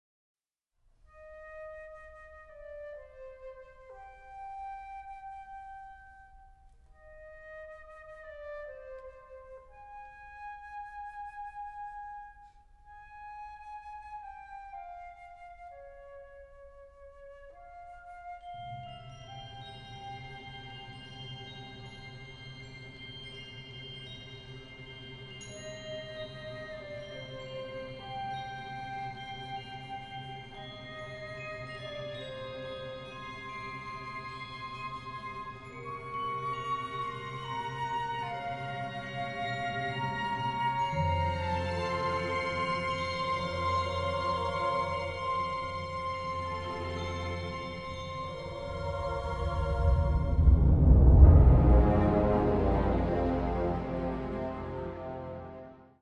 magic in the air